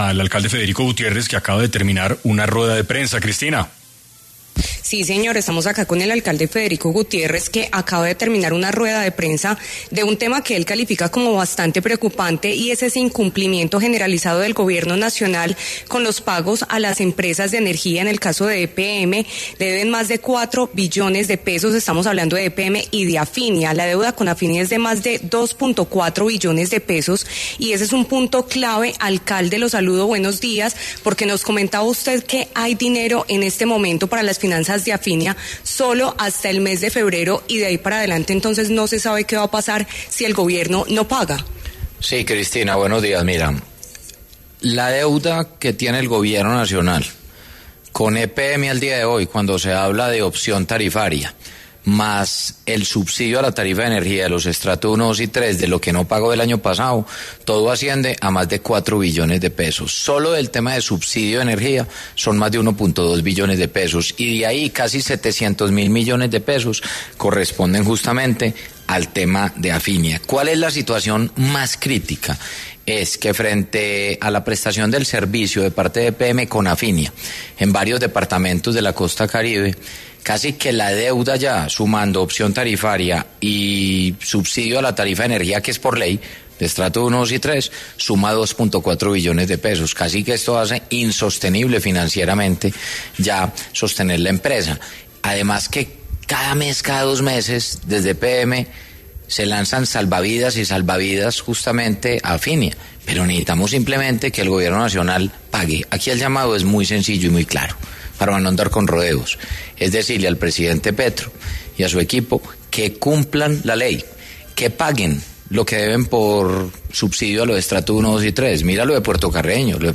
En 6AM de Caracol Radio estuvo Federico Gutiérrez, alcalde de Medellín, quien habló sobre la crisis energética que hay en varios departamentos de la costa caribe